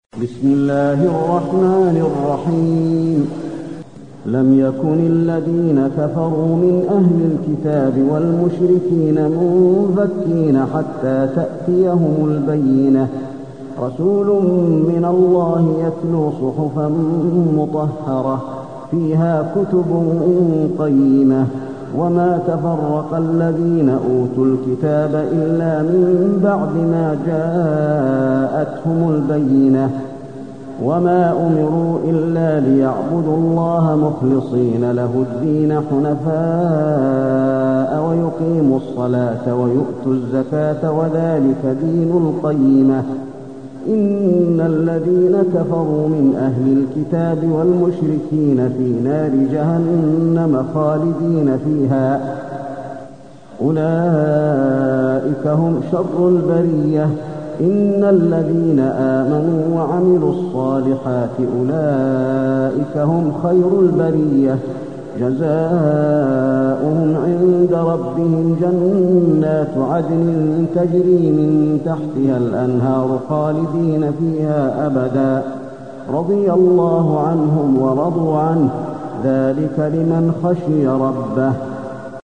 المكان: المسجد النبوي البينة